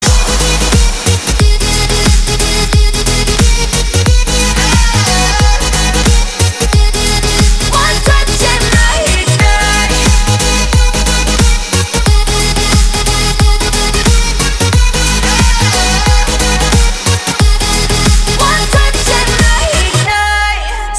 • Качество: 320, Stereo
громкие
мощные
Electronic
EDM
Downtempo